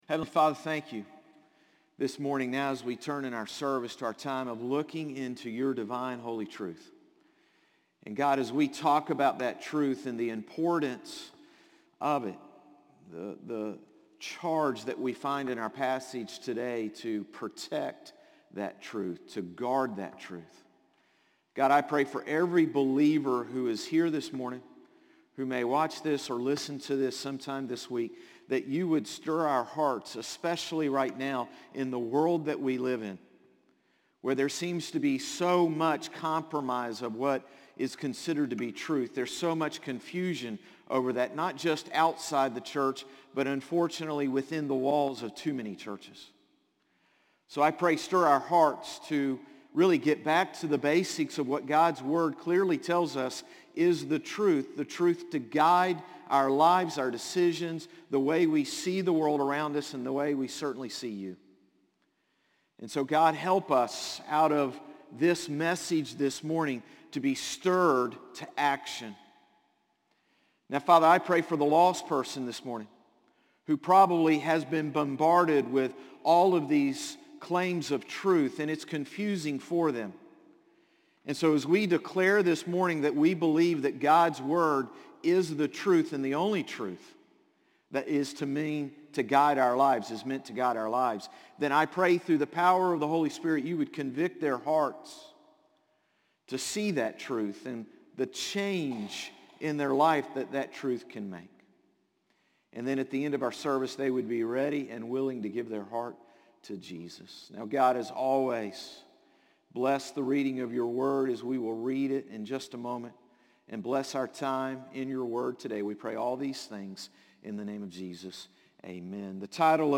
Sermons - Concord Baptist Church
Morning-Service-9-18-22.mp3